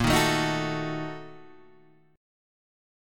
A# Major 7th Suspended 4th Sharp 5th
A#M7sus4#5 chord {6 6 7 8 7 5} chord